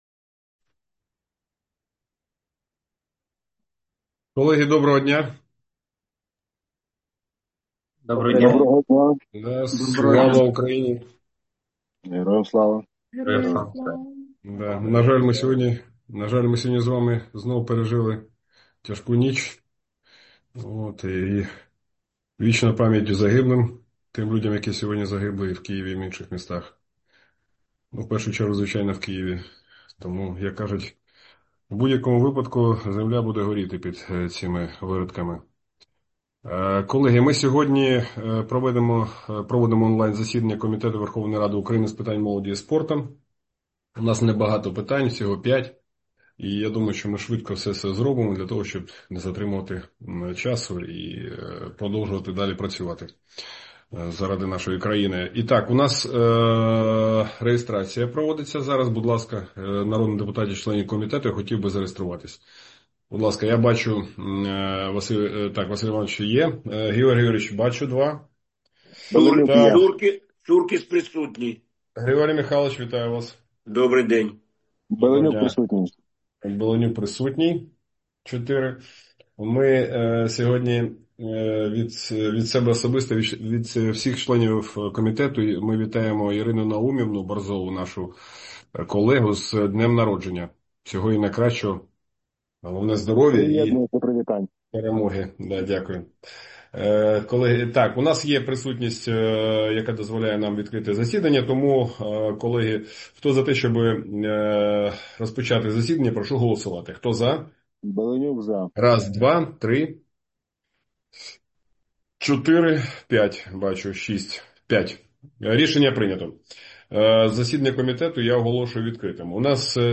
Аудіозаписи засідання Комітету у серпні 2025 року